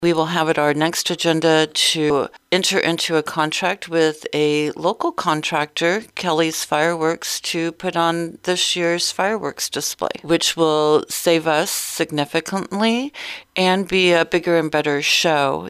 The City of Chillicothe will save on fireworks this year by going with a local provider for the annual Independence Day display.  City Administrator Roze Frampton explained that the city council gave tentative approval.